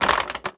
Toilet Paper Roll Sound
household